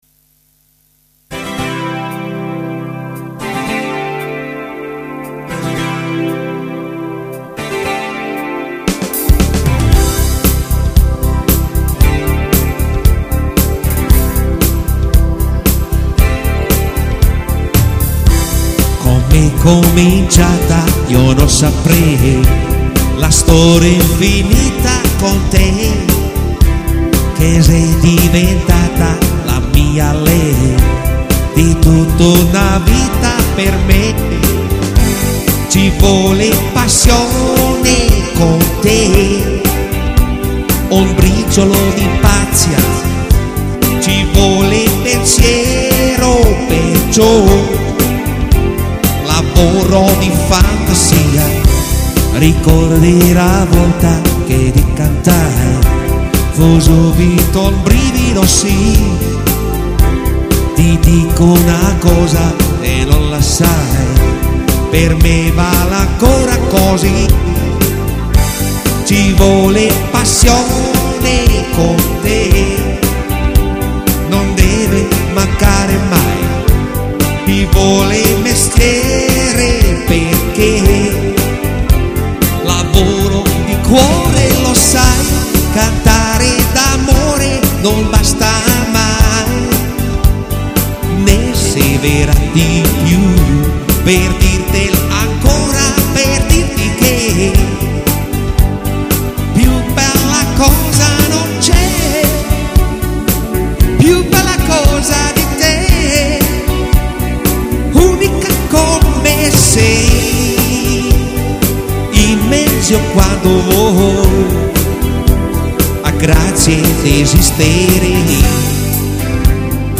• Coverband
• Alleinunterhalter